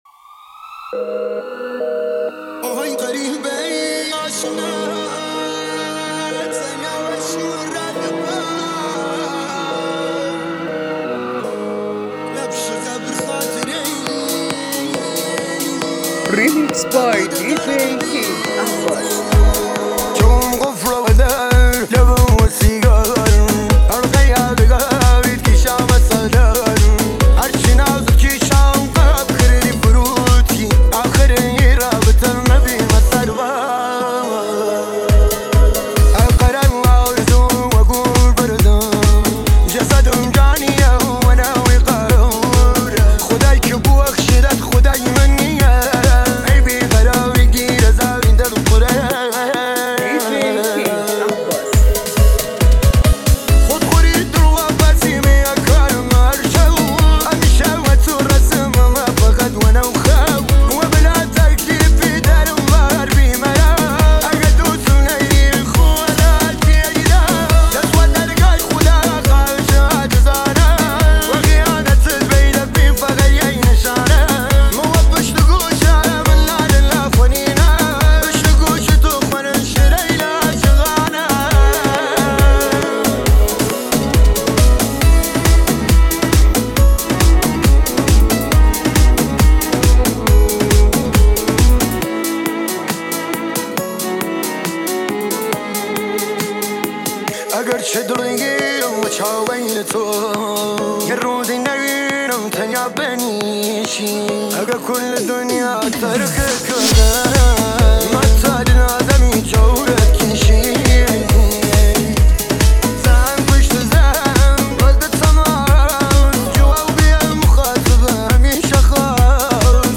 • آهنگ جدید ~ ریمیکس
ریمیکس اضافه شد